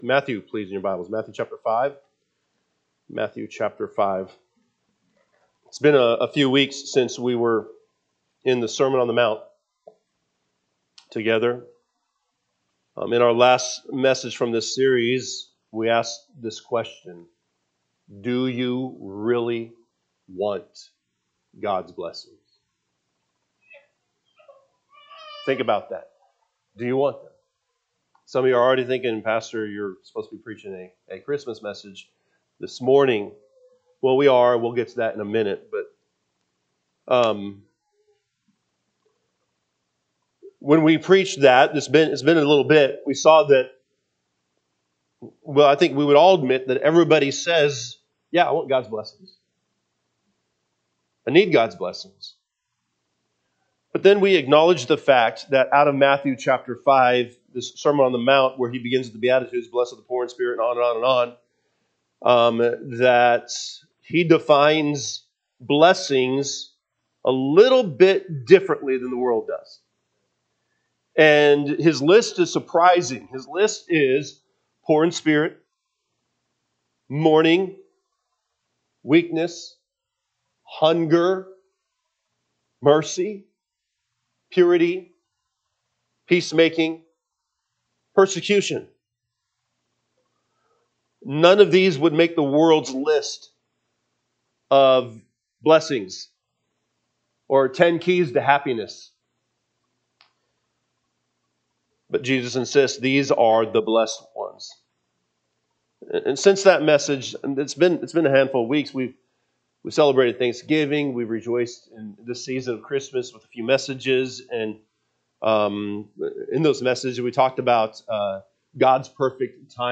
Sunday AM Message
December 21, 2025 am Service Matthew 5:1-12 (KJB)